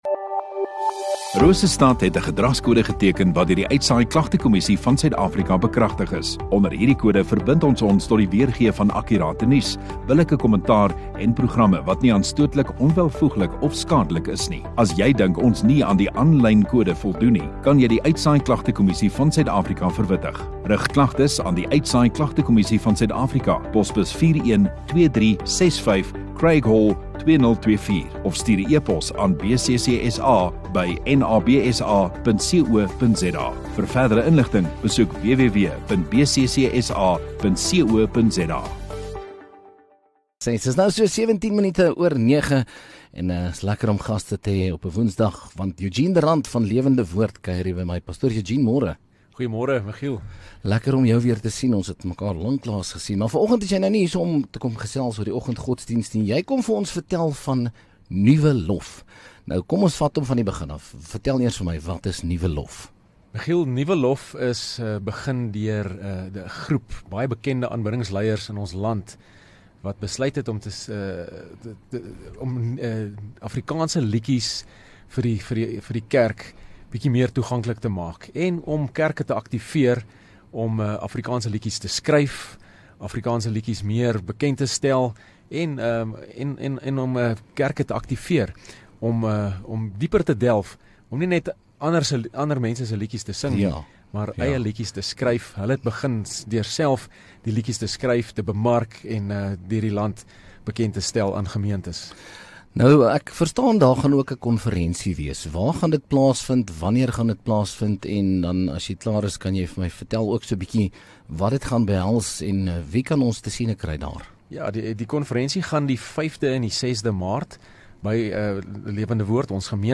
Radio Rosestad View Promo Continue Radio Rosestad Install Gemeenskap Onderhoude 12 Feb Nuwe Lof